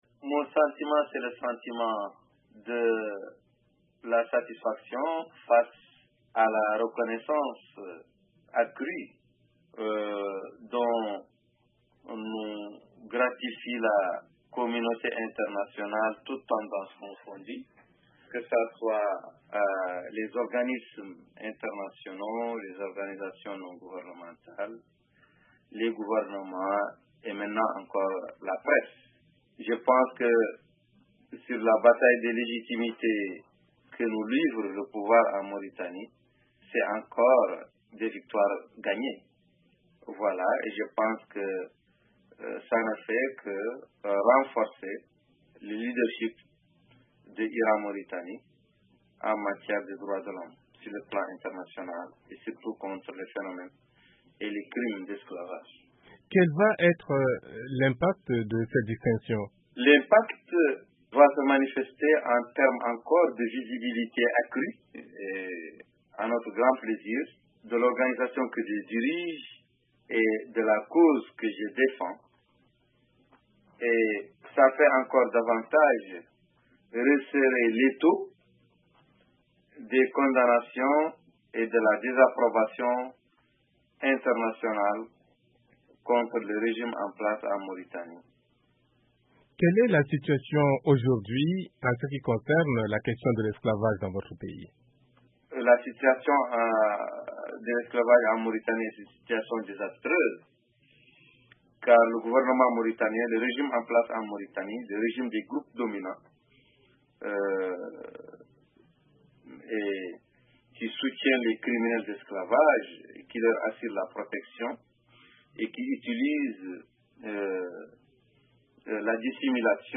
Un entretien avec le militant anti-esclavagiste Biram Dah Abeid